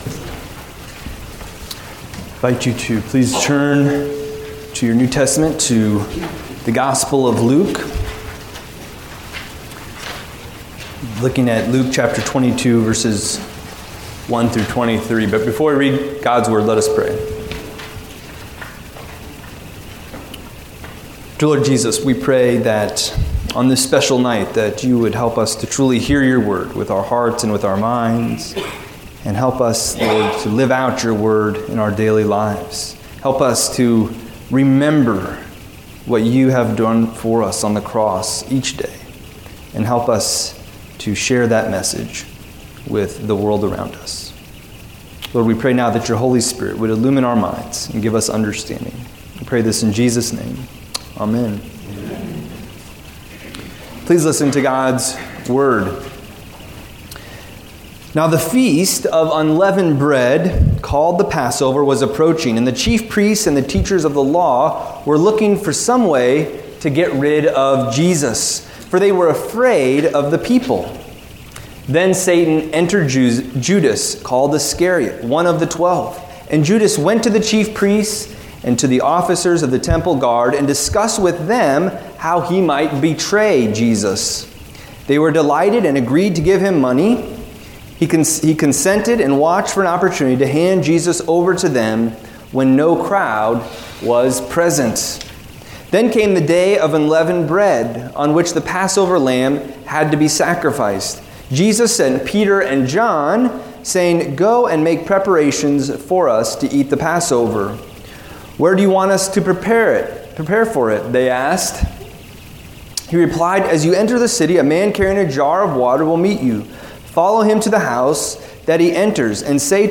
Service Type: Maundy Thursday